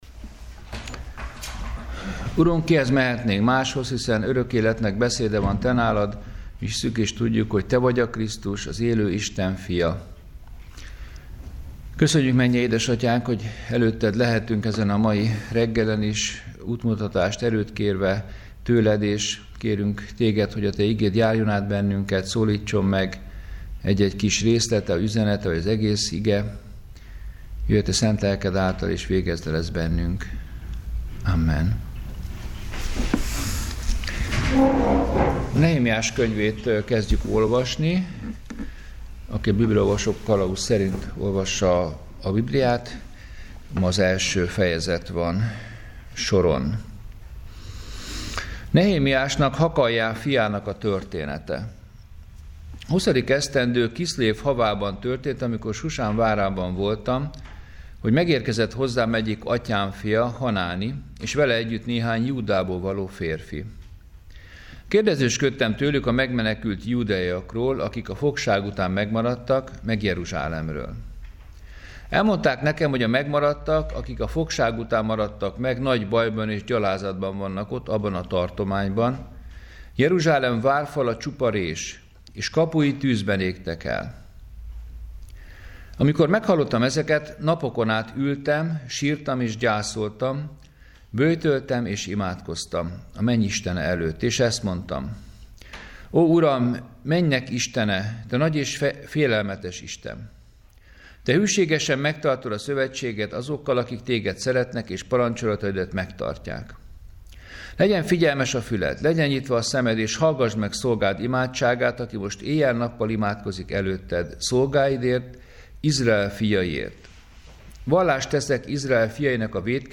Áhítat, 2019. február 27.